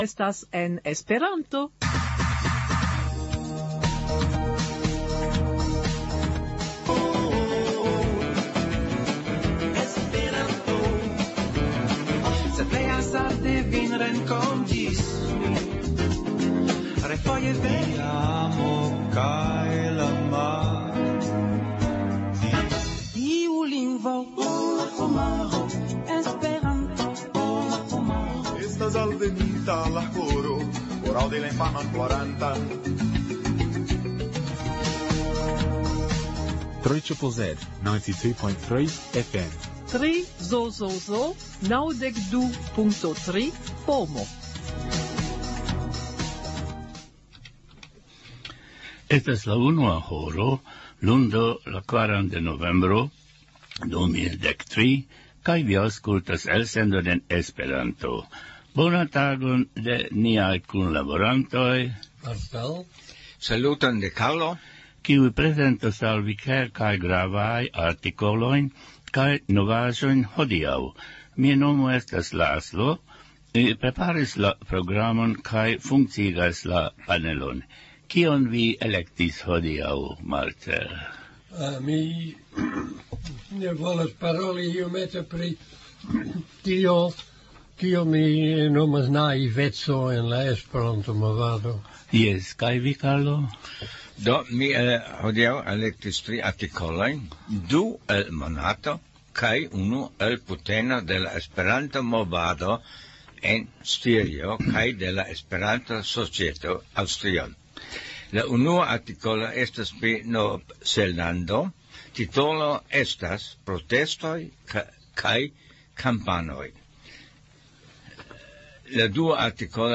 Legado